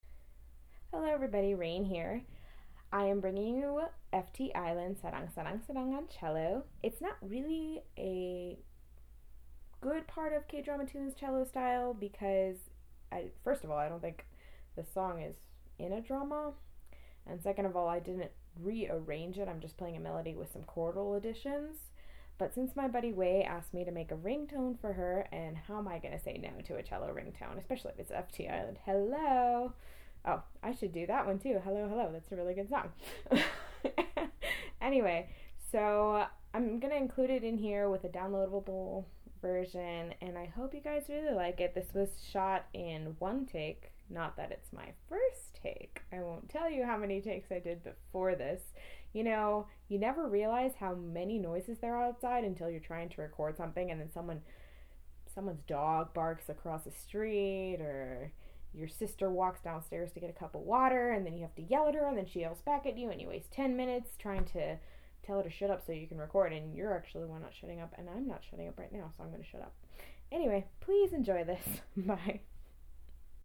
Also – love your verbal intro! Great to hear your voice – which sounds lovely too, btw ^^